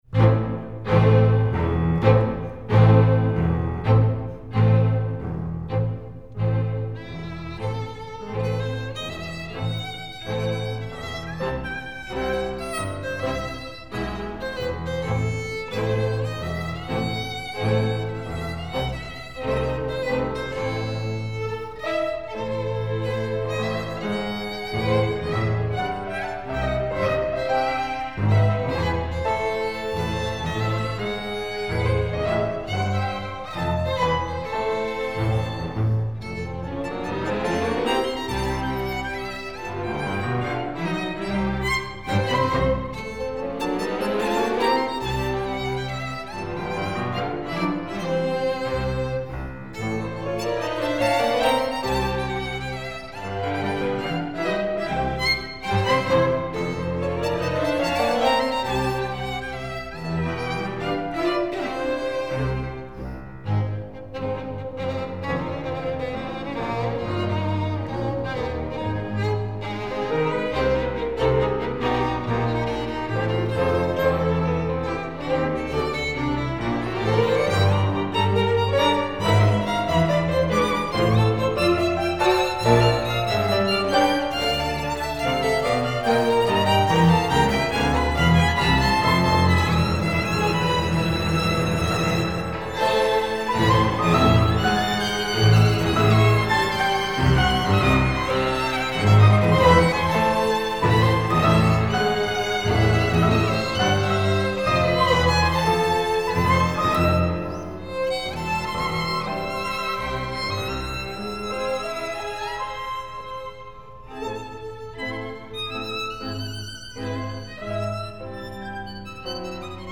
8Bughici_Moderato.mp3